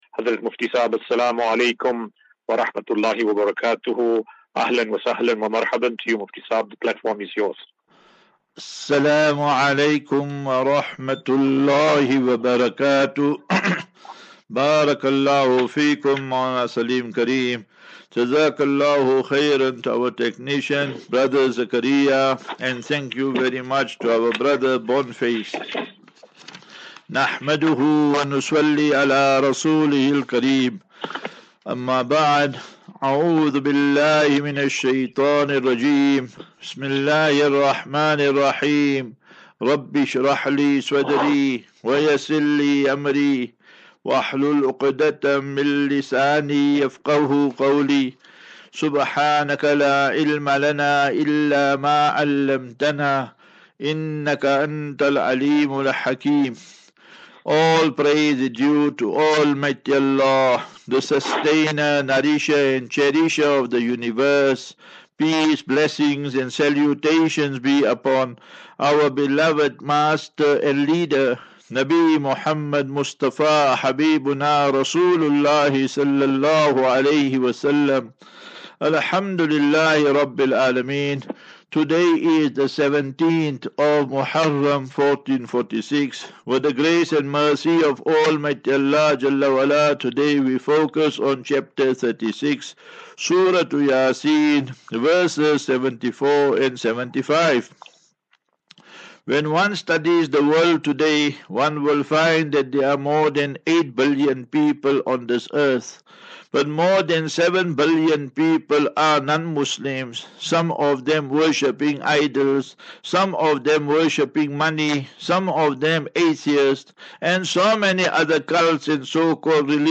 View Promo Continue Install As Safinatu Ilal Jannah Naseeha and Q and A 24 Jul 24 July 2024.